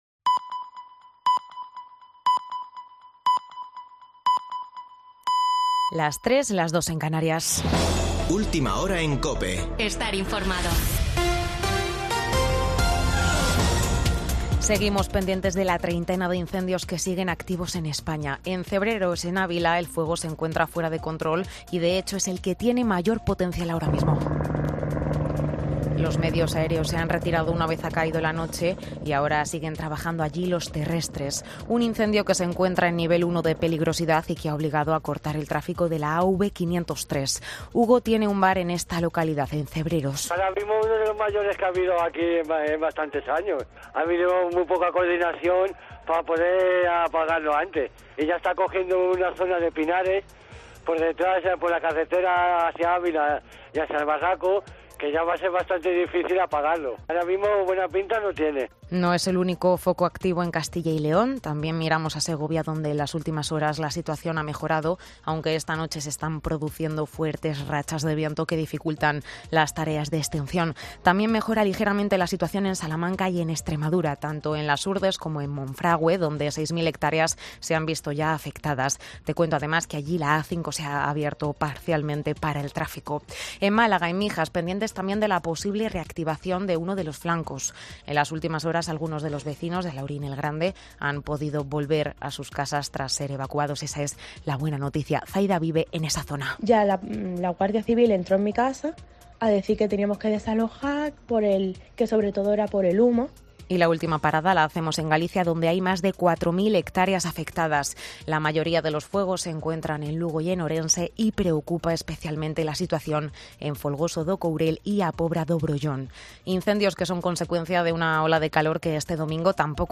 Boletín de noticias de COPE del 17 de julio de 2022 a las 03:00 horas